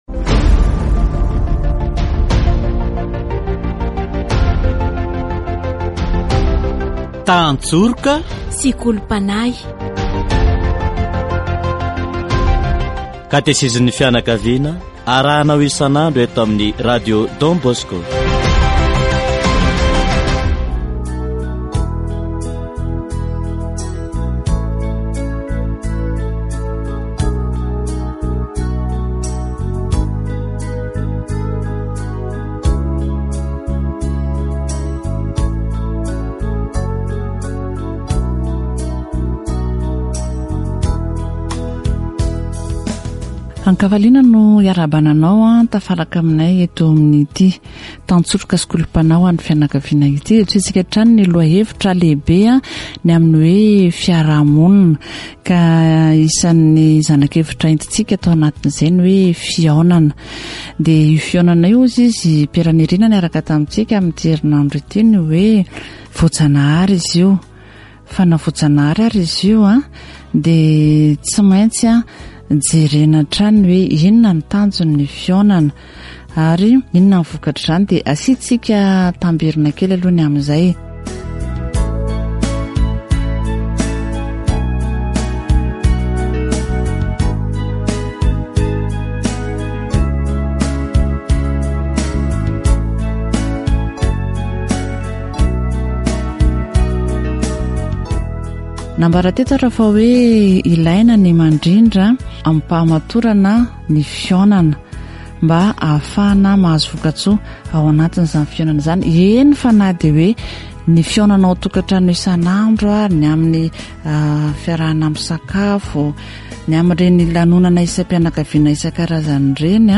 The best places to meet are safe, protected places, with educators, with parents (family, school, church, organization). Catechesis on meeting, exchange of ideas